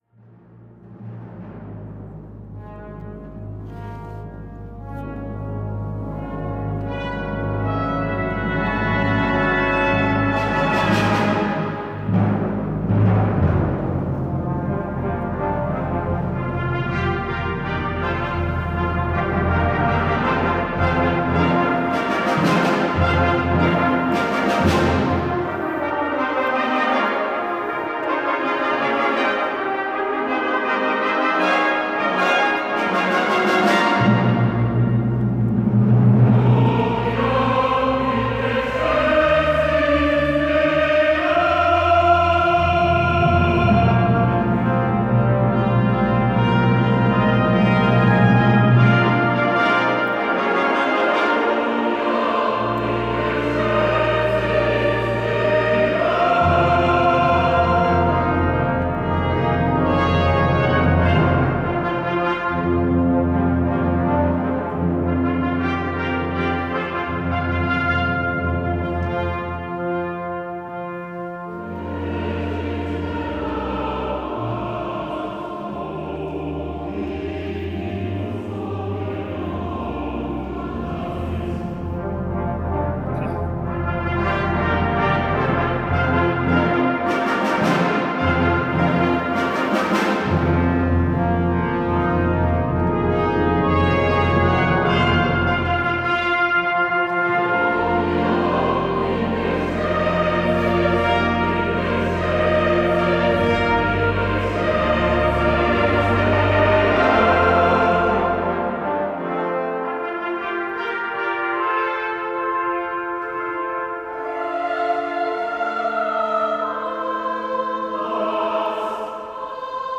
Ausschnitt aus dem Konzert „John Rutter: Gloria“ vom 07.